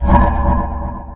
anomaly_gravy_blast00.ogg